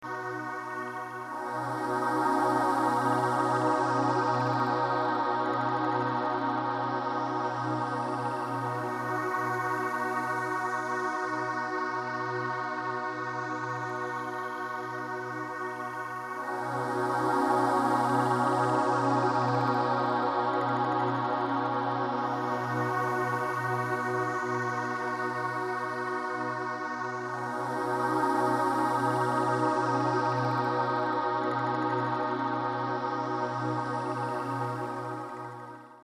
Infine ho realizzato un suono elettronico di Synth / Pad come tappeto armonico sia per chiarire l' armonia con passaggi molto semplici, sia per dare un suono particolare che unisse tutte le parti.
Butterflypad.mp3